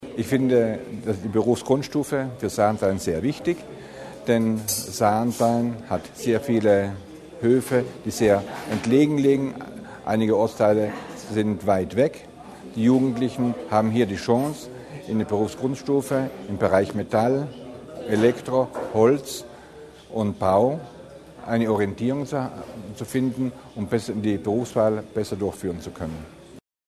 Landeshauptmann Durnwalder zur Bedeutung der Berufsbildung